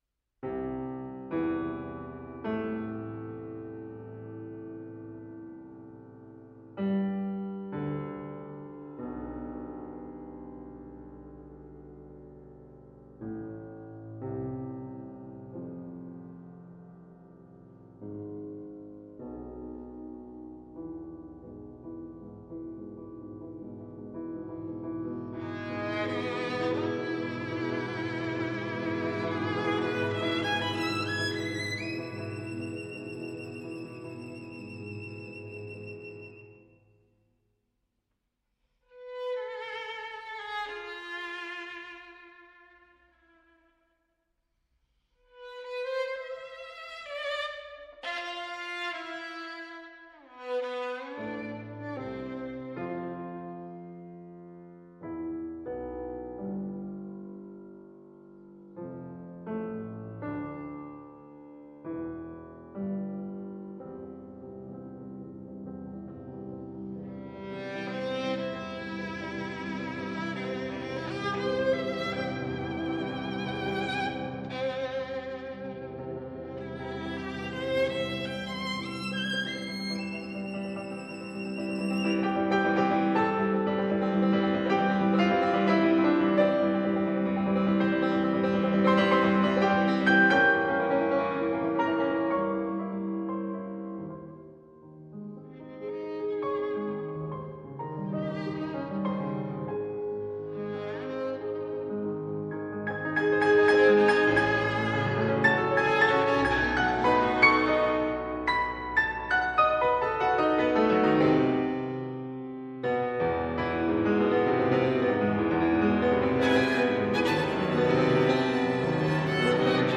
Possibly the greatest piano virtuoso of all time, Liszt studied and played at Vienna and Paris and for most of his life toured throughout Europe giving concerts.
Style: Classical
violin